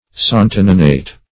Santoninate \San"to*nin`ate\, n.